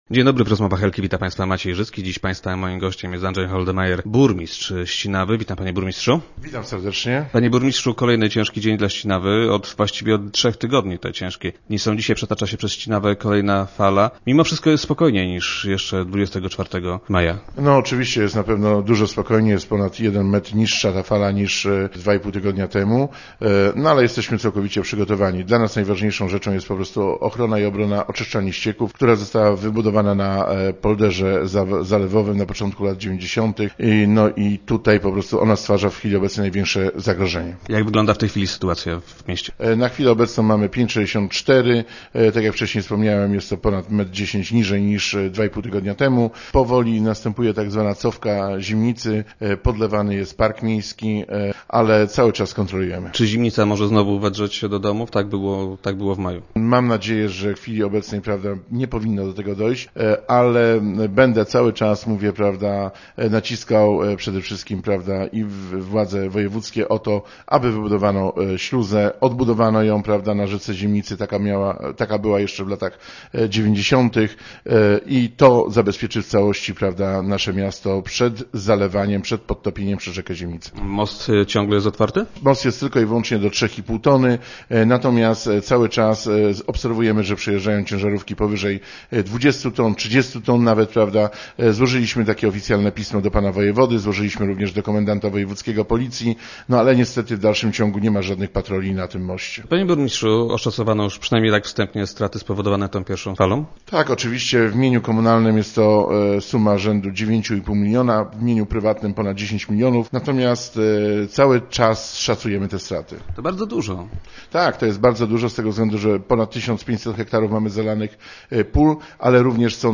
- Mówiłem wojewodzie podczas spotkania w telewizyjnym programie, że wybudowanie czterech kilometrów wałów okalających ścinawski polder, uchroni miasto przed kolejnymi powodziami. Niestety nic się w tym kierunku nie robi. Ja jestem gotów taki wał wybudować, ale musiałbym na ten cel otrzymać jakieś środki - mówił w dzisiejszych Rozmowach Elki burmistrz Ścinawy.